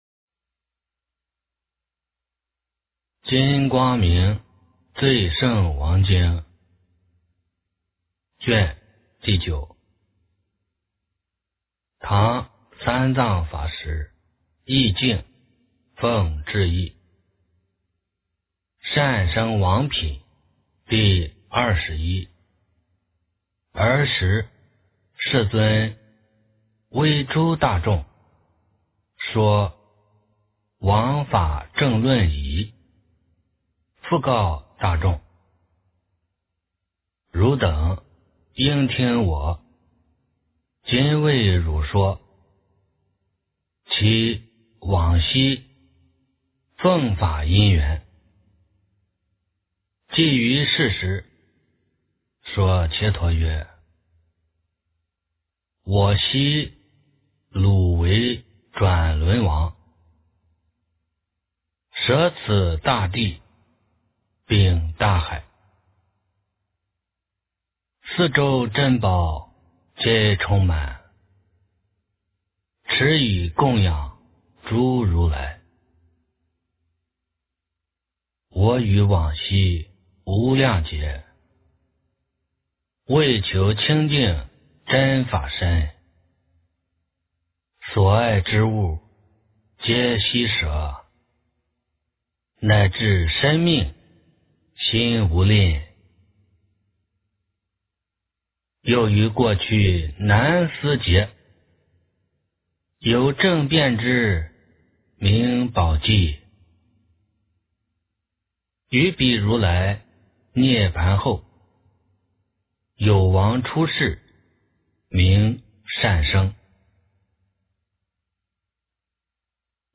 金光明最胜王经9 - 诵经 - 云佛论坛